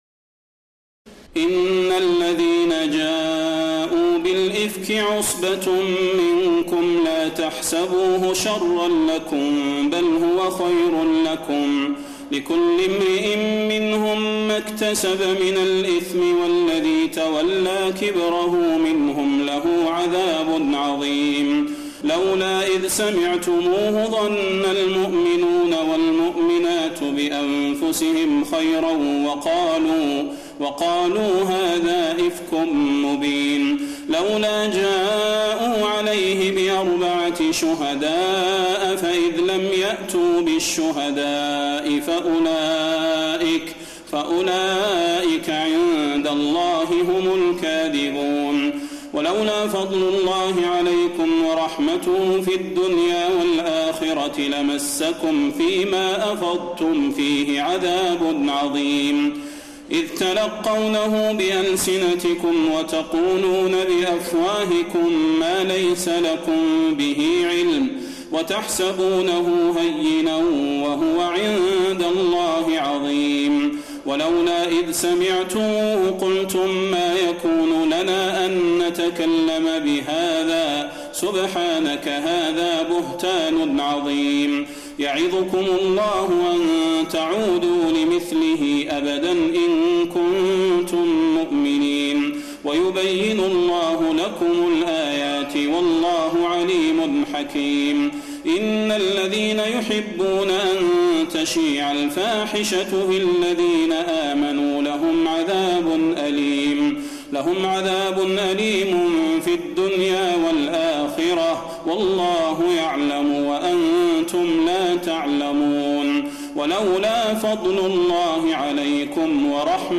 تراويح الليلة السابعة عشر رمضان 1432هـ من سورتي النور (11-64) و الفرقان (1-20) Taraweeh 17 st night Ramadan 1432H from Surah An-Noor and Al-Furqaan > تراويح الحرم النبوي عام 1432 🕌 > التراويح - تلاوات الحرمين